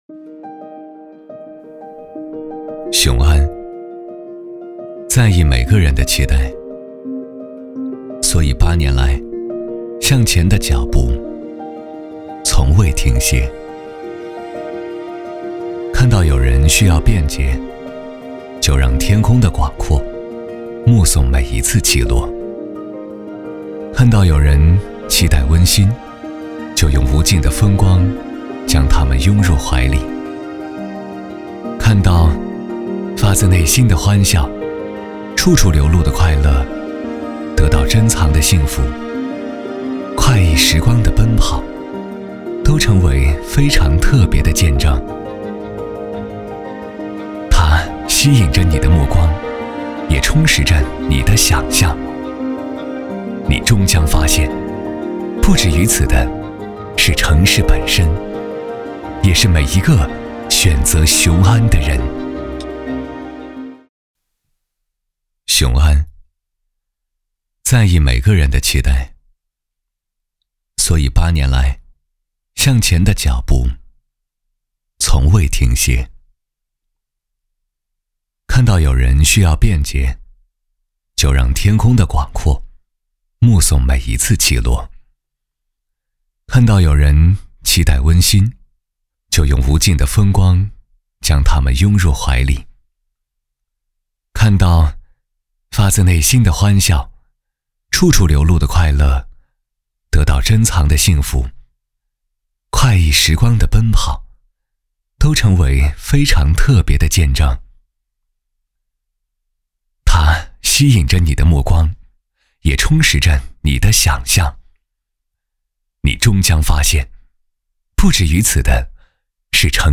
晚会颁奖配音-配音样音免费在线试听-第1页-深度配音网
男349-颁奖-雄安8周年.mp3